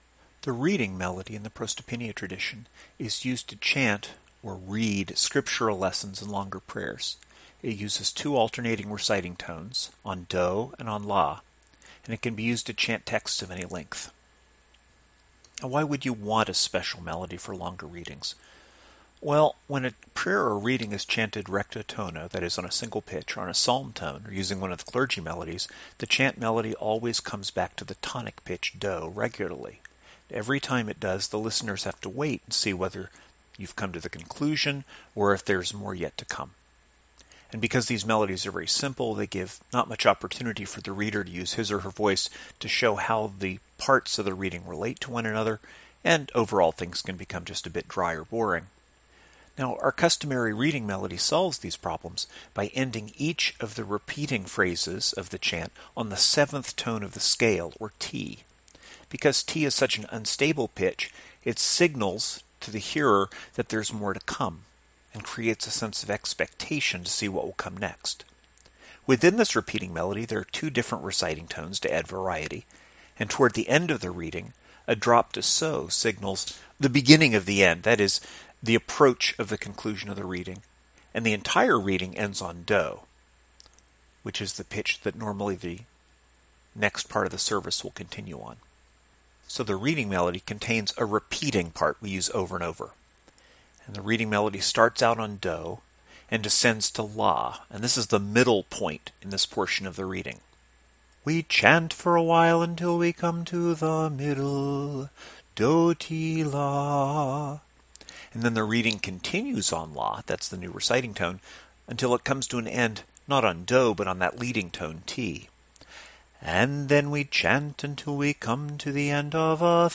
The reading melody is used to chant ("read") Scriptural lessons and longer prayers. It uses two alternating reciting tones (do and la), and can be employed to chant texts of any length.
Reading_melody_tutorial.mp3